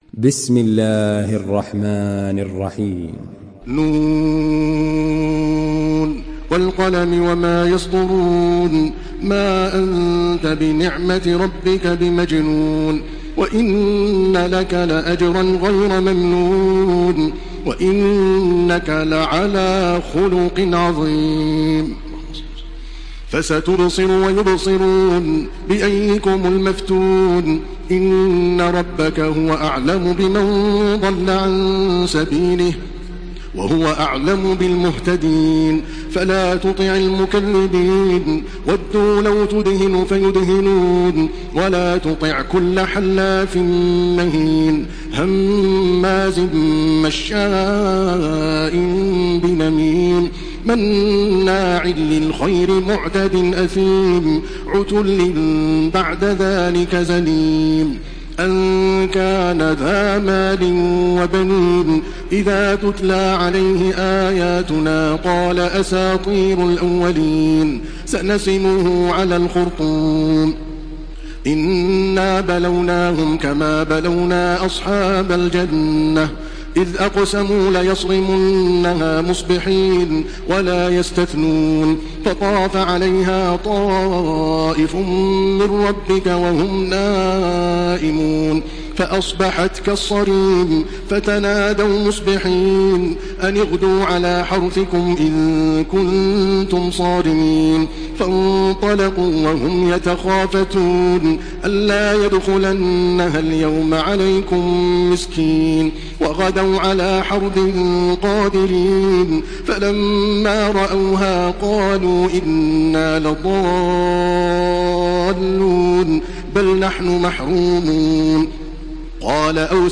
Surah আল-ক্বালাম MP3 in the Voice of Makkah Taraweeh 1429 in Hafs Narration
Surah আল-ক্বালাম MP3 by Makkah Taraweeh 1429 in Hafs An Asim narration.